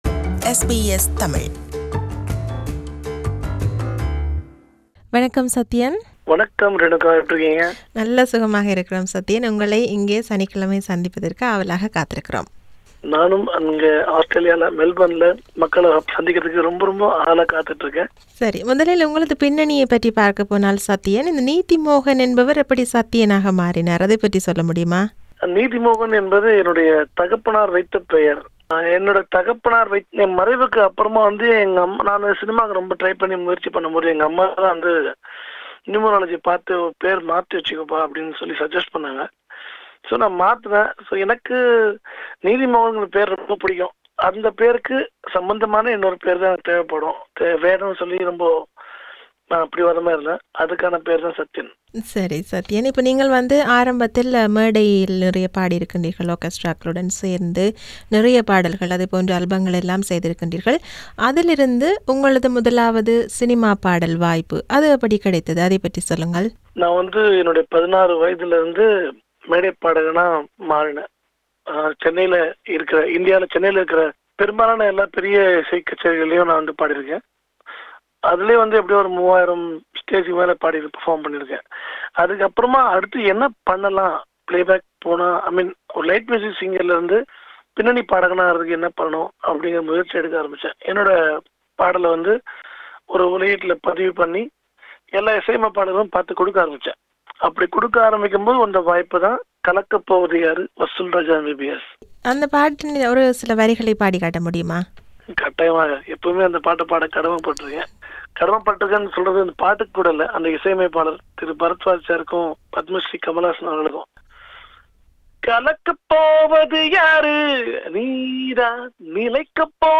This is an interview with him.